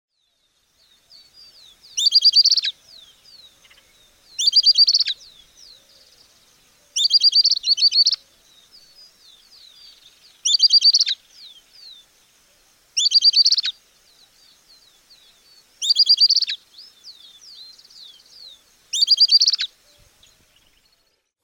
Gaucho Serrano Piojito Silbón
piojitosilbon.mp3